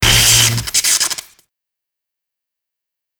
I wanted a more detailed sound, so i tried combining it with RA2's, and with some adjusting, it turned out pretty nice.
combined RA1 & RA2 Tesla coil sounds for presence